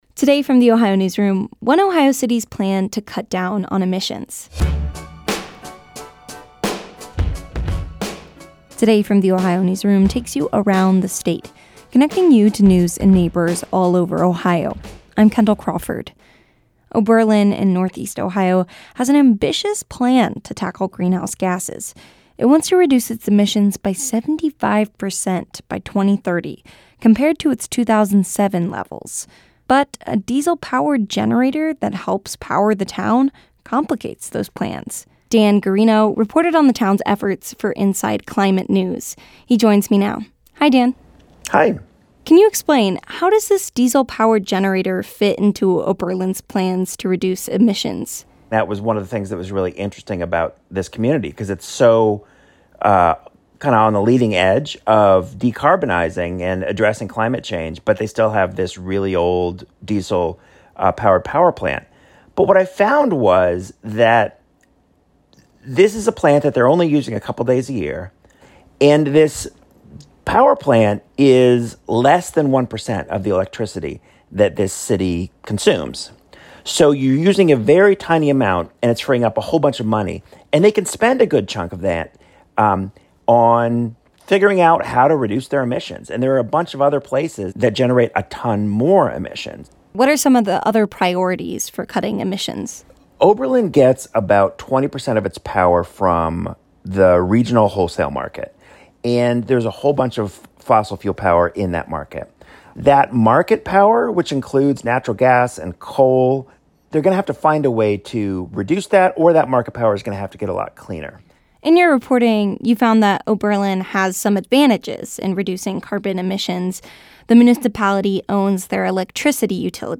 This interview has been edited for brevity and clarity.